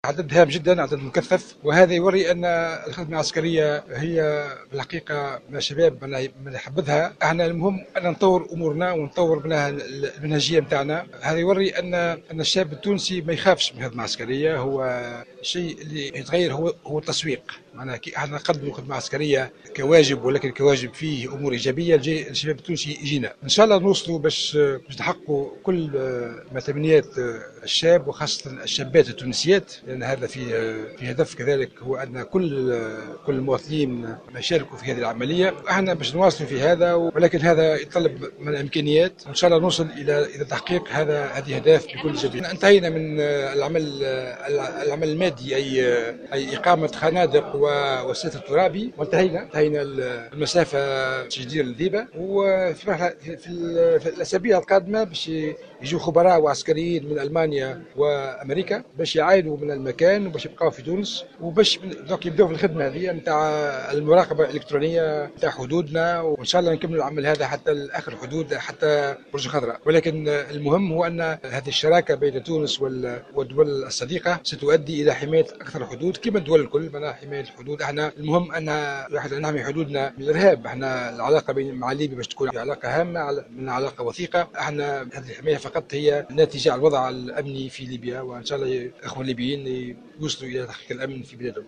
تحدّث وزير الدفاع الوطني فرحات الحُرشاني خلال إشرافه مساء اليوْم الجمعة على اختتام اشغال المائدة المستديرة حول السياسة الدفاعية بتونس العاصمة عن مدى إقبال الشباب على حملة التجنيد الاستثنائي ومشروع تجنيد الشابات الراغبات في أداء الخدمة الوطنية.